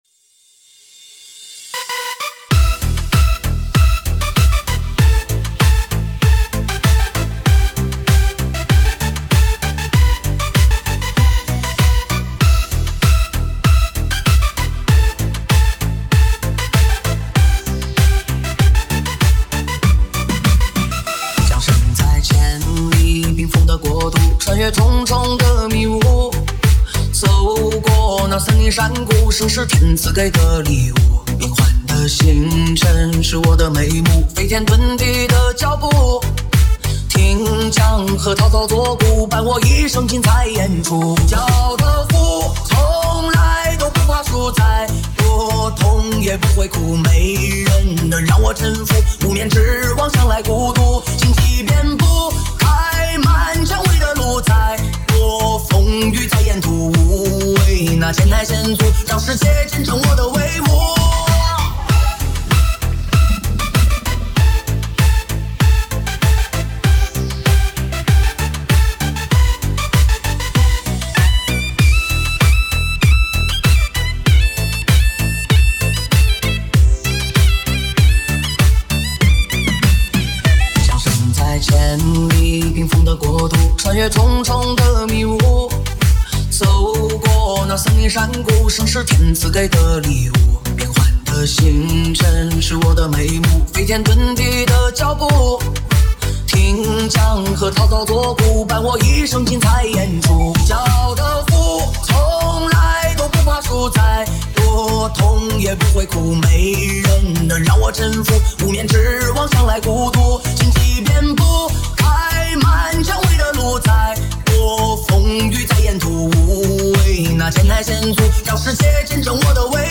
dj舞曲
分类： 交谊舞曲、电音DJ舞曲
车载U盘DJ